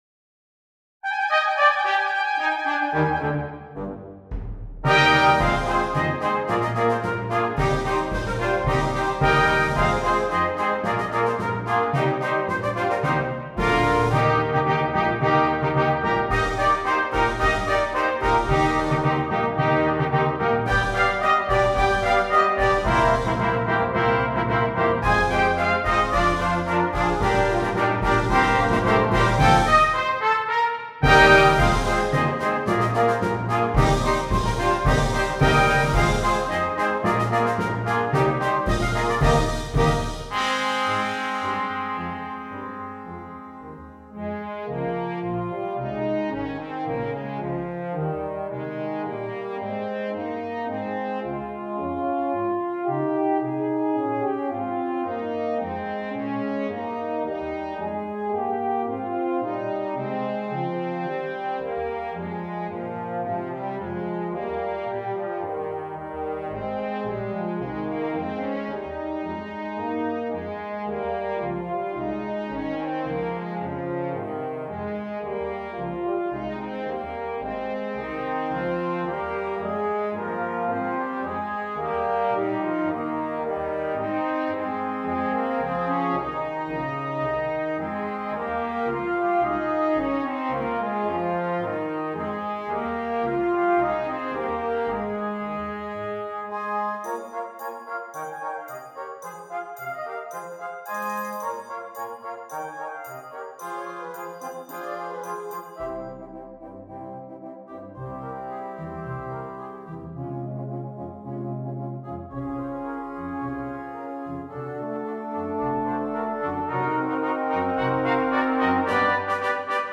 Double Brass Quintet